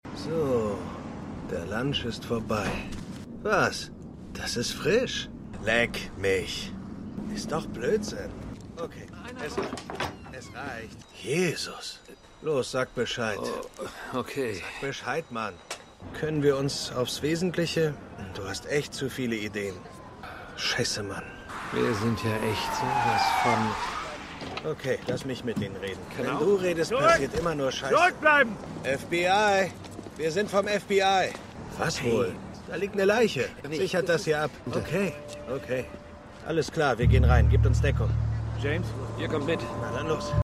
Synchronfirma: Studio Funk GmbH & Co. KG Postperfect [Hamburg]